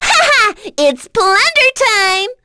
kingsraid-audio / voices / heroes / en / Miruru-vox-get-02.wav
Miruru-vox-get-02.wav